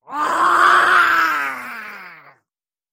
Звуки гоблина
Здесь вы найдете рычание, скрежет, зловещий смех и другие устрашающие эффекты в высоком качестве.
Зловещий звук атаки гоблина